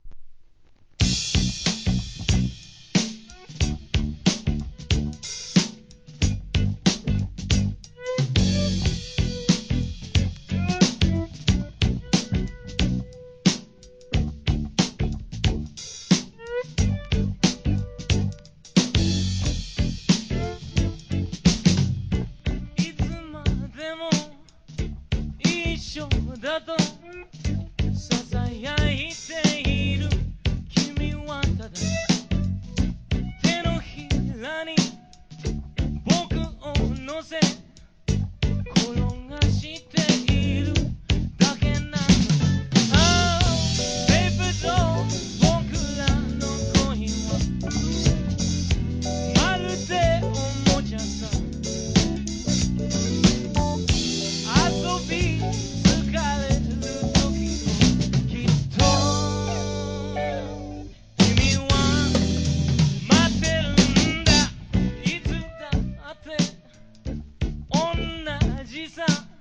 ¥ 1,650 税込 関連カテゴリ SOUL/FUNK/etc...
人気ドラムブレイク